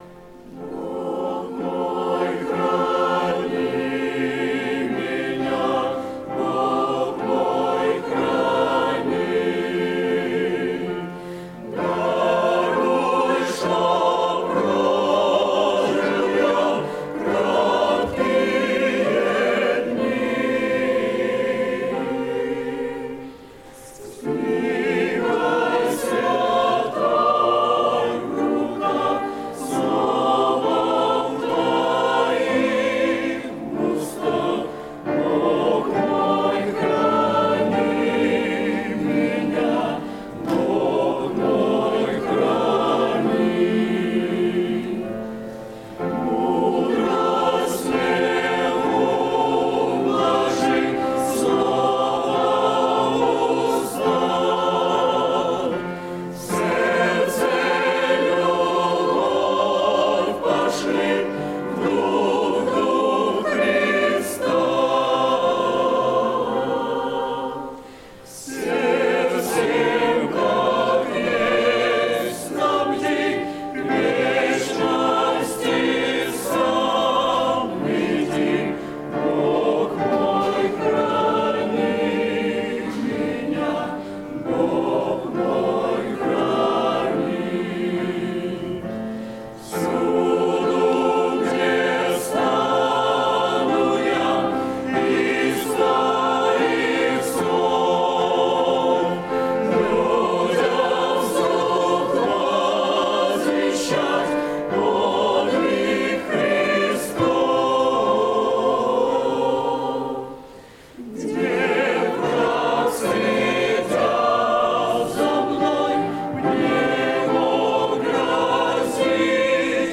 Богослужение 08.09.2019
Бог мой, храни меня - Хор (Пение)[